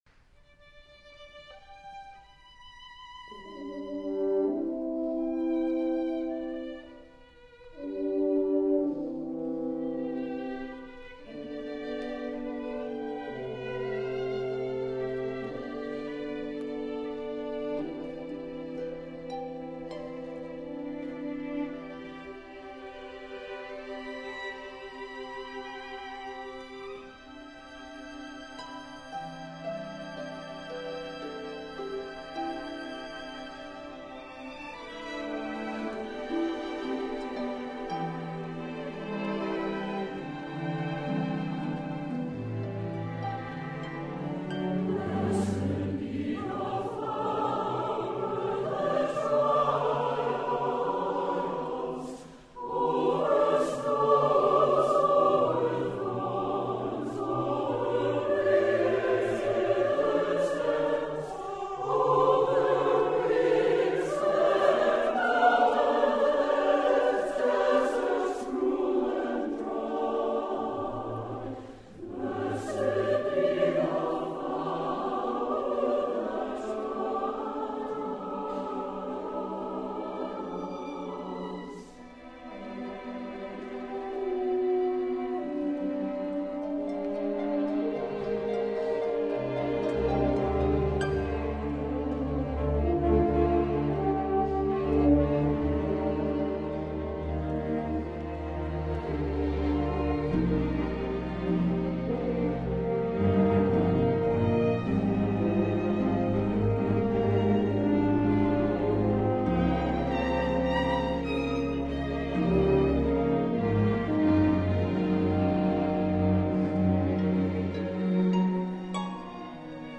SATB, 2 horns, harp, strings